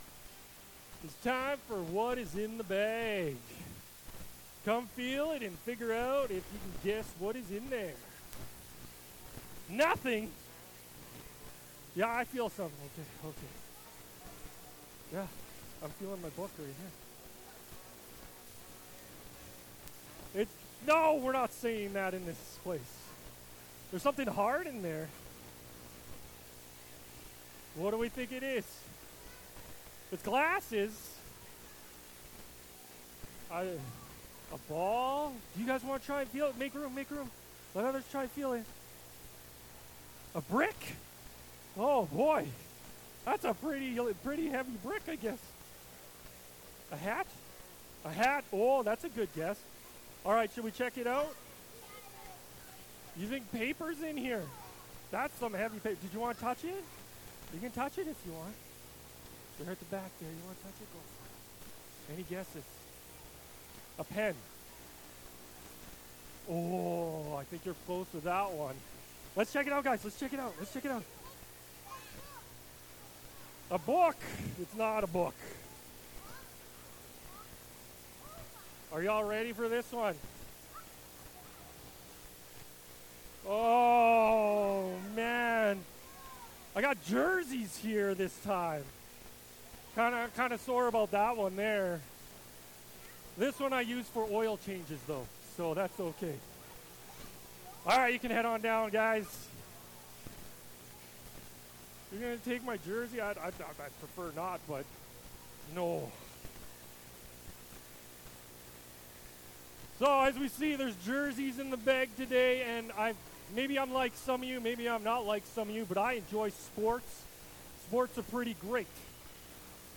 Sermons | Muir Lake Community Alliance Church